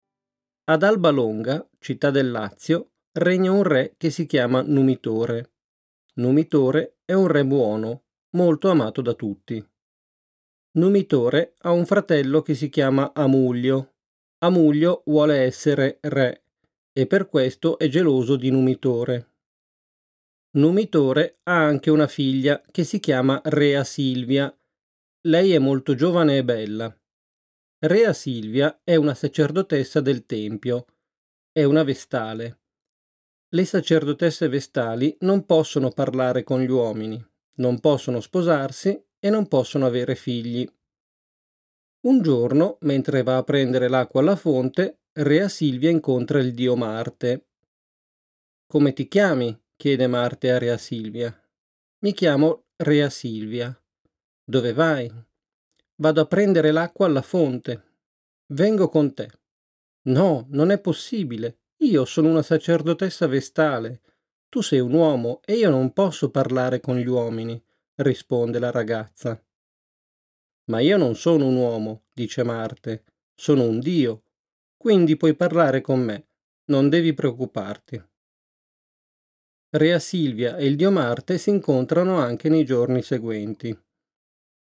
Audiokniha v italštině vás seznámí se sedmi z mnoha legend, které můžeme najít v římské historii. Poslechnete si pověsti o založení města, o únosu Sabinek a o souboji mezi Horatii a Curiatii, dozvíte se o kapitolských husách a poznáte příběh o překročení Rubikonu a další. Texty jsou zpracovány pro začátečníky a mírně pokročilé studenty italštiny a jsou namluvené rodilým Italem.